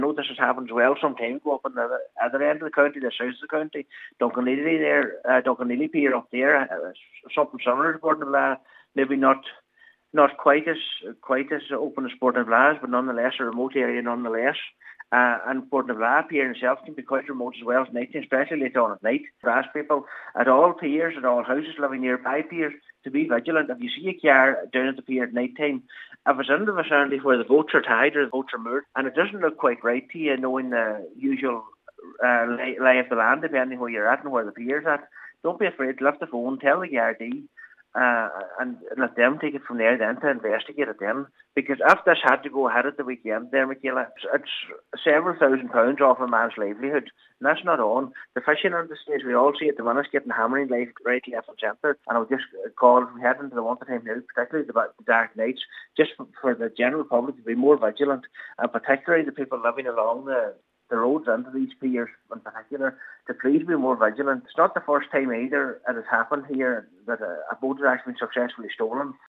Councillor Michael McClafferty says the theft of engines comes at a huge financial cost to those targeted: